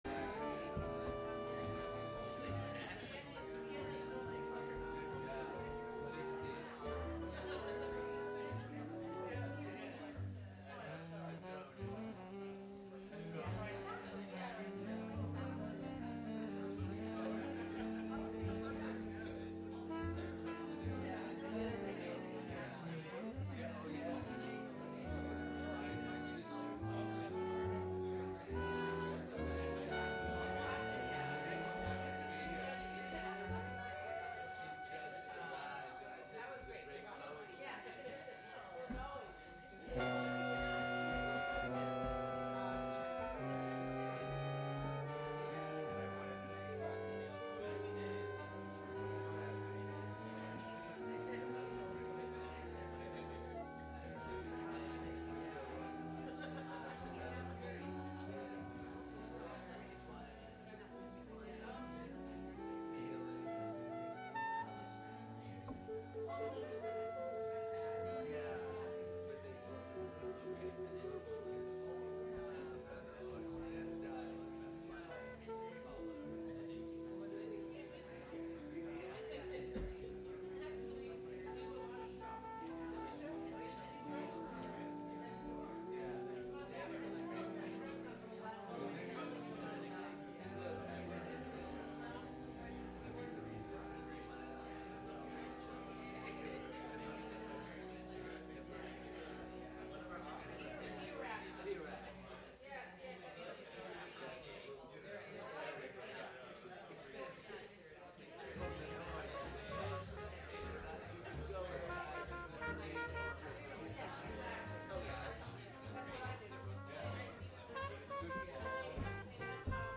Live from The Flow Chart Foundation